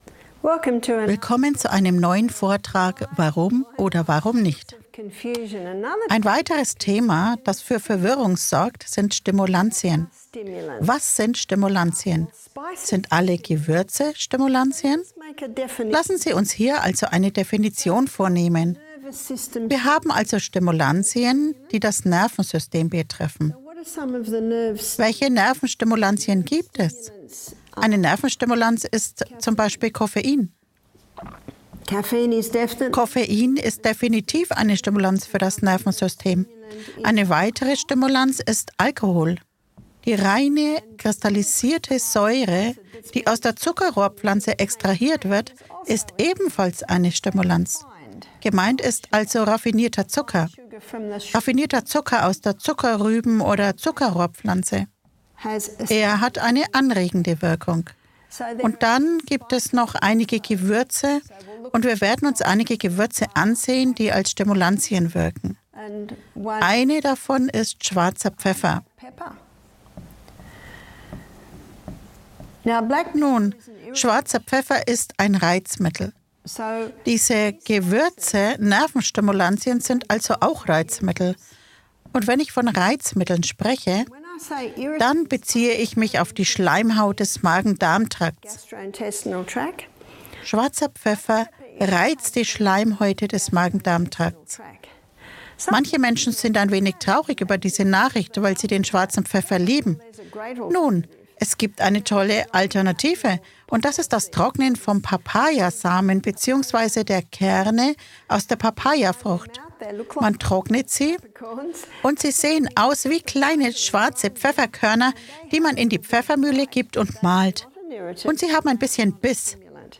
In diesem aufschlussreichen Vortrag wird das Thema Stimulantien beleuchtet. Von Koffein und Alkohol bis hin zu Gewürzen wie schwarzem und Cayenne-Pfeffer wird erklärt, wie diese die Gesundheit beeinflussen. Erfahren Sie, welche Alternativen es für gereizte Magen-Darm-Trakte gibt und entdecken Sie die heilenden Wirkungen von Ingwer und Cayenne-Pfeffer.